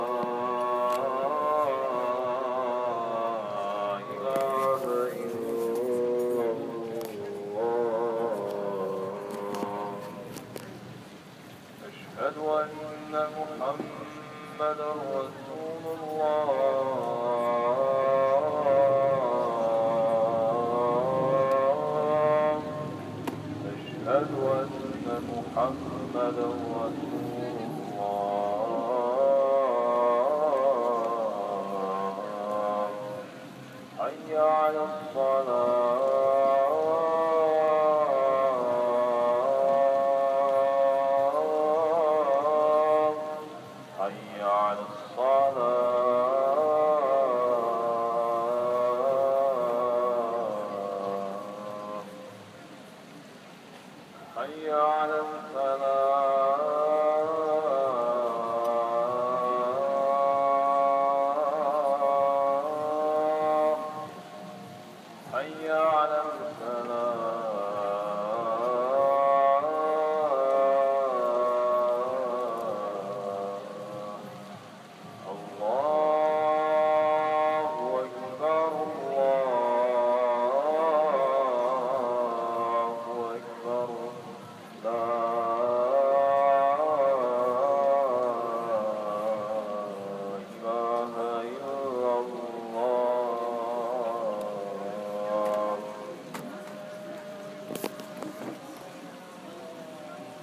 Call to Prayer
call-to-prayer-mostar.m4a